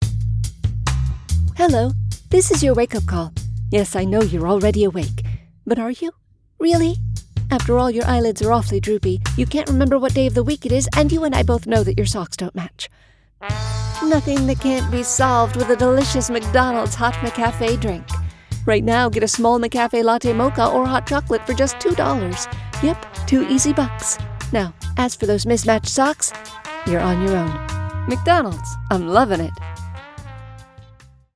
McCafe demo commercial